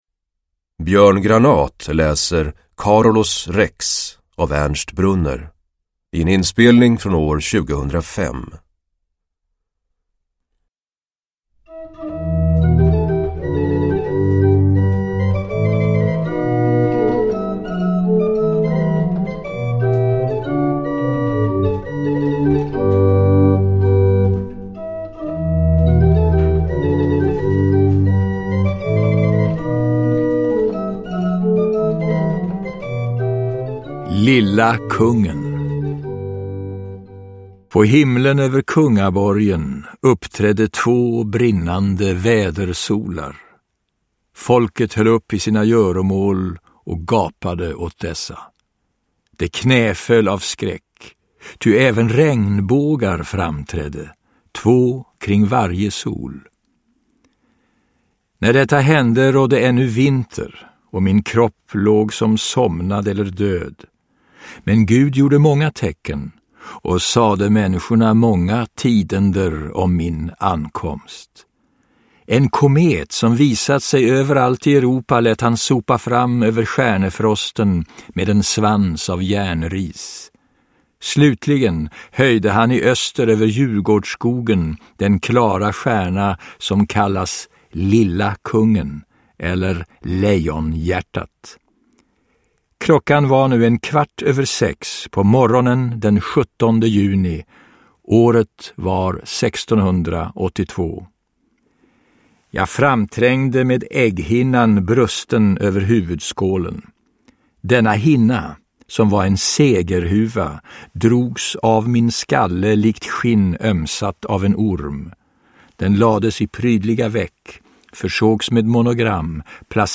Uppläsare: Björn Granath